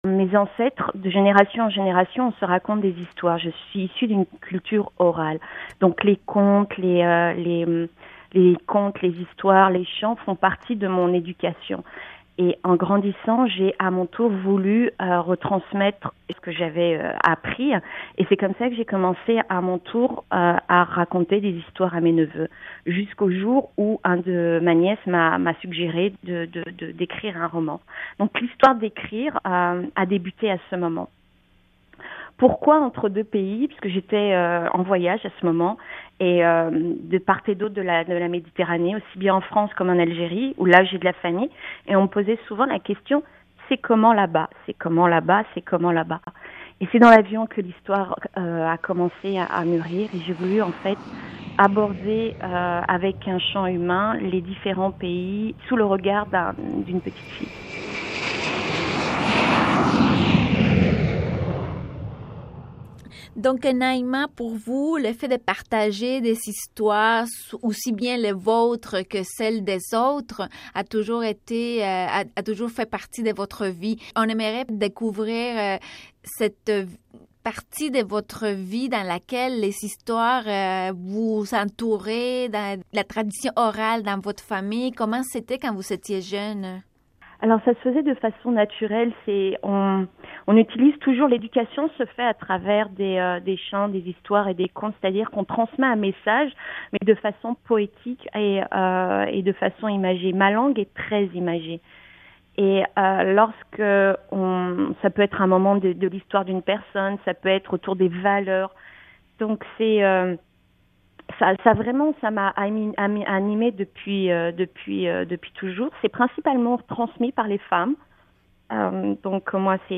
Dans une entrevue portant sur son roman, ses ancêtres et ses motivations pour l’écrire